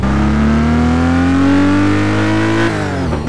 Index of /server/sound/vehicles/lwcars/lotus_esprit